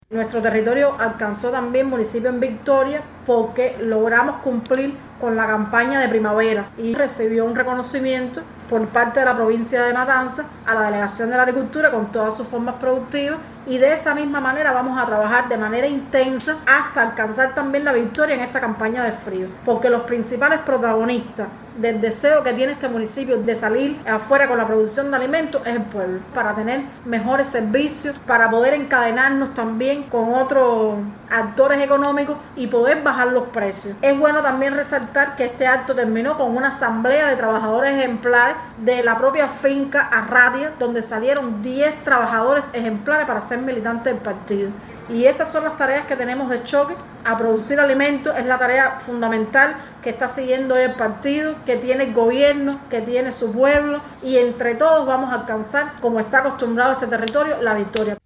Martínez Martínez significó además los resultados positivos del municipio en la campaña de primavera, así como los trabajadores destacados que integrarán las filas del Partido en el territorio.